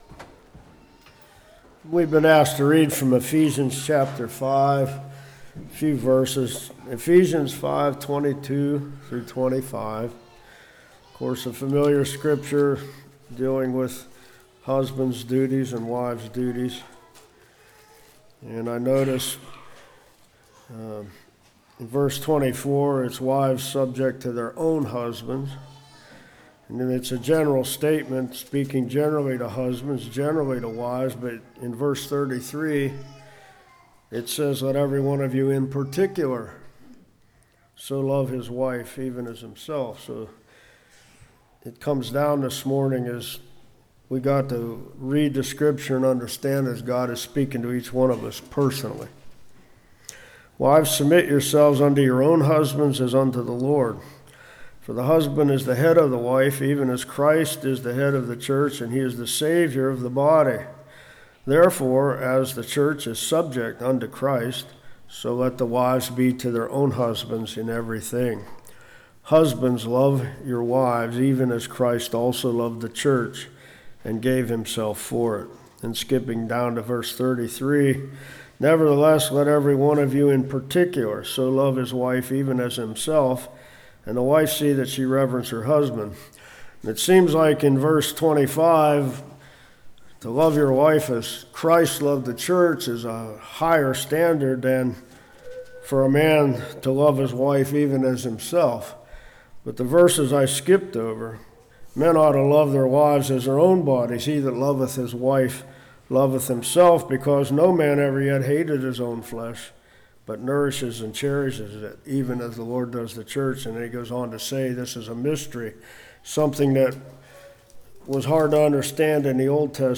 33 Service Type: Morning How women control Effects on children What wives should do « Jews